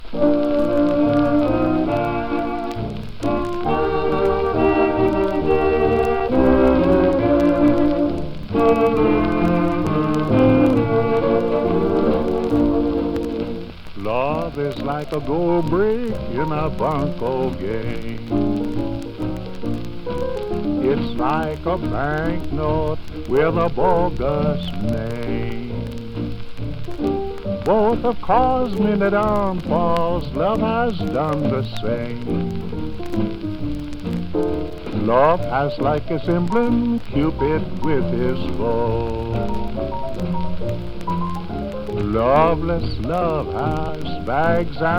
Jazz, Pop　USA　12inchレコード　33rpm　Mono
ジャケ汚れ　盤良好レーベル部シール貼付有　見開きジャケ　元音源に起因するノイズ有